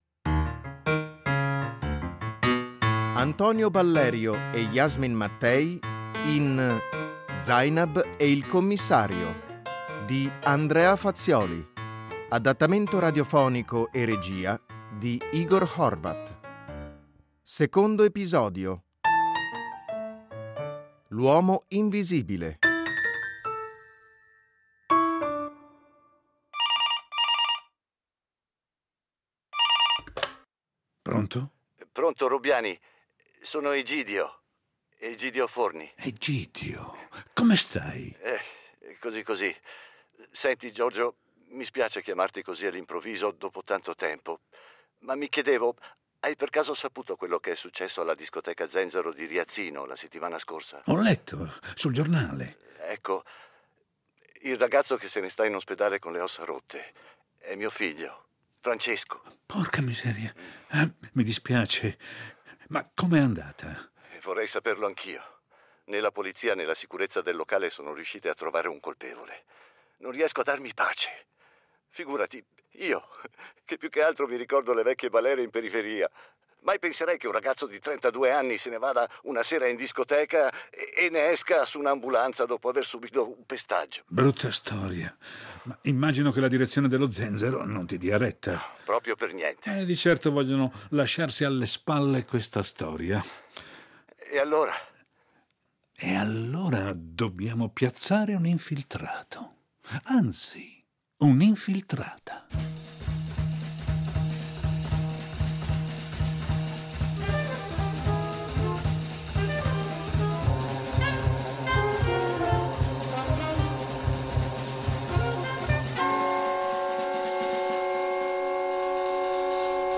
adattamento radiofonico
L’occasione giusta per avviare un nuovo formato nella produzione di fiction radiofonica: puntate brevi, caratterizzate da una narrazione dinamica e dal ritmo incalzante.